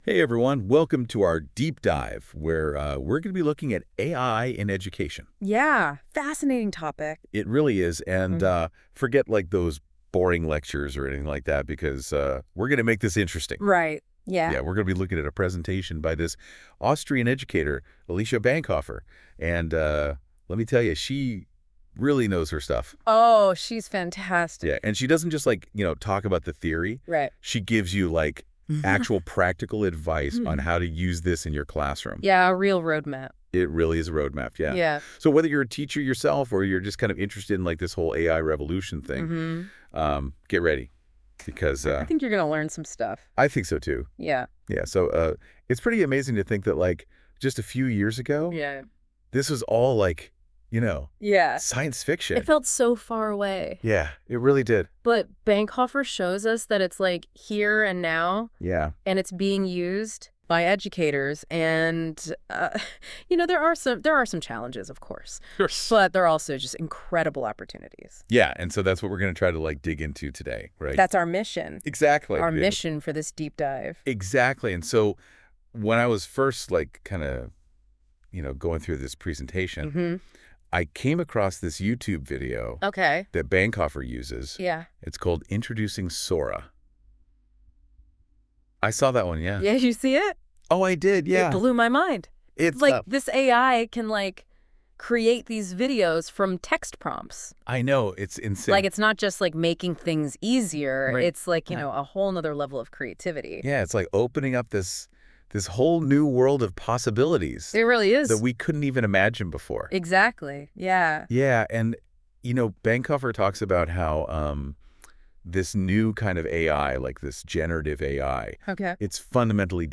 Und als KI-Bonus gibt es hier einen vom Google-Tool NotebookLM erzeugten englischsprachigen Podcast über die Webinar-Inhalte.